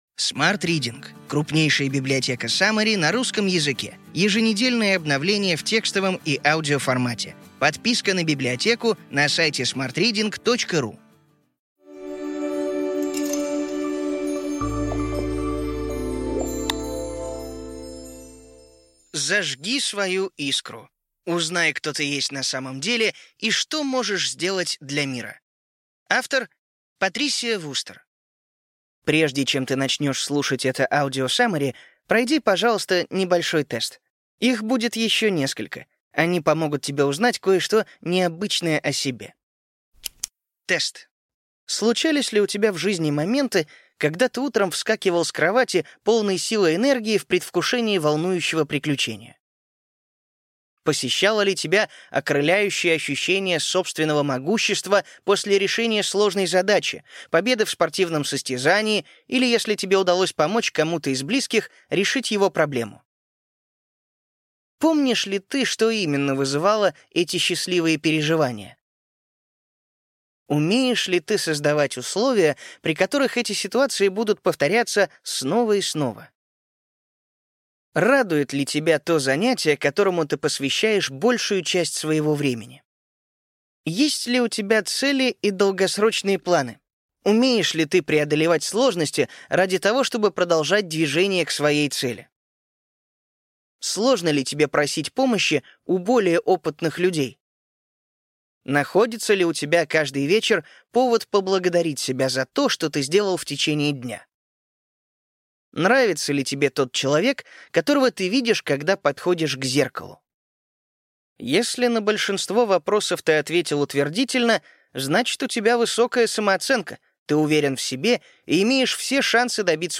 Аудиокнига Ключевые идеи книги: Зажги свою искру! Узнай, кто ты есть на самом деле и что можешь сделать для мира.